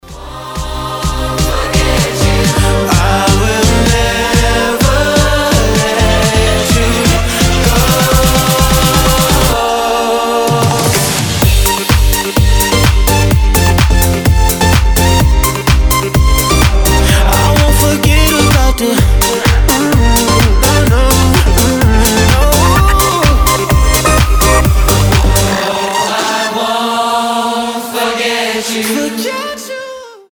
громкие
EDM